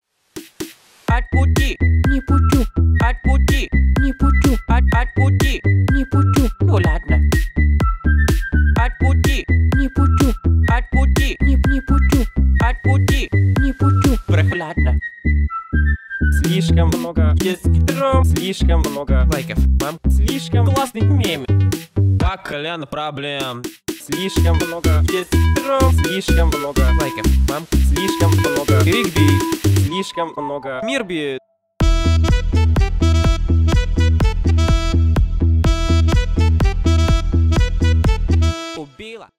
весёлые # клубные